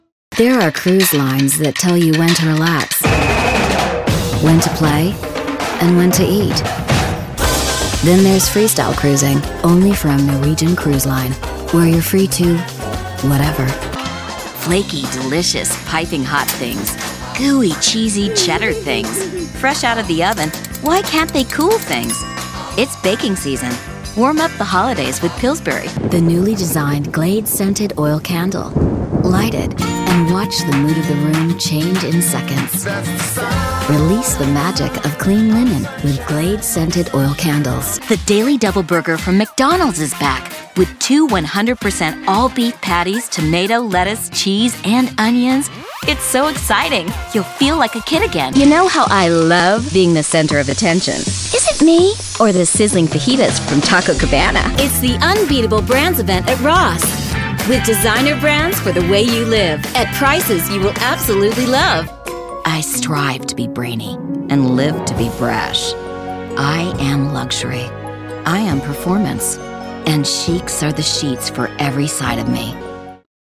COMMERCIAL DEMO
COMMERCIAL-DEMO_shorter-.mp3